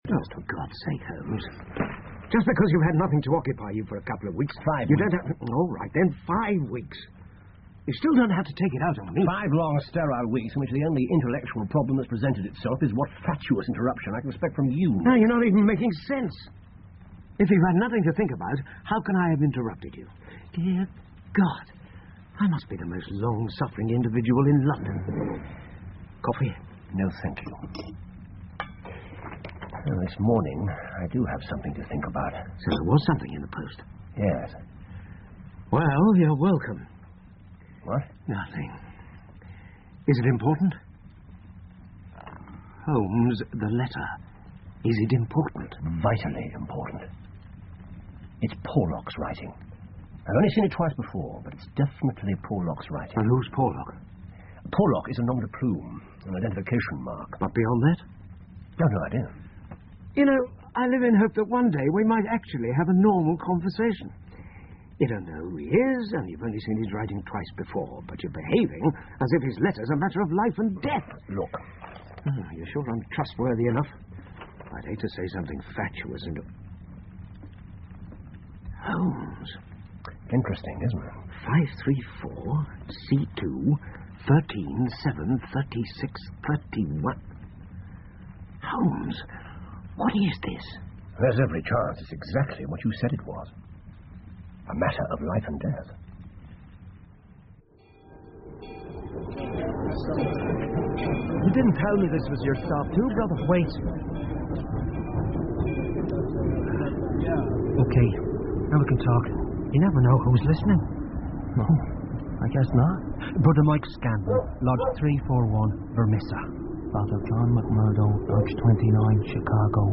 福尔摩斯广播剧 The Valley Of Fear - Part 01-2 听力文件下载—在线英语听力室